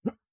Jump2.wav